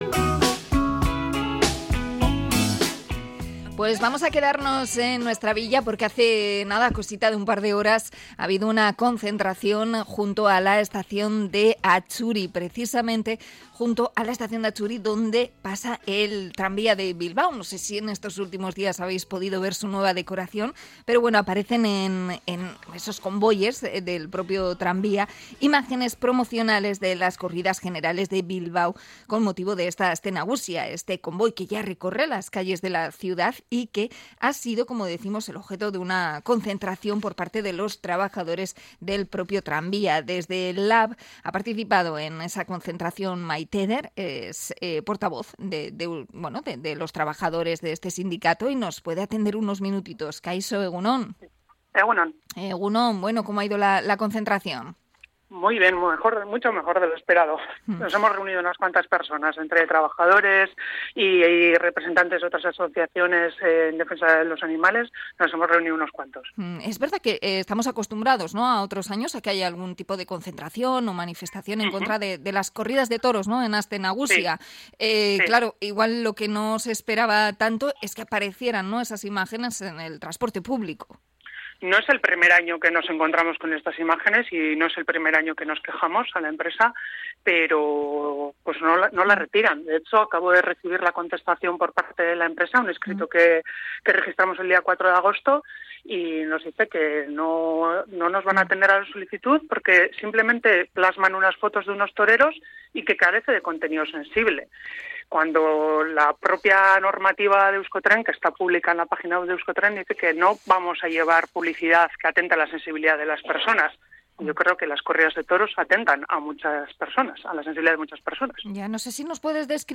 Entrevista al sindicato LAB por la publicidad taurina del tranvía de Bilbao